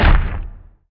SFX monster_footstep_giant.wav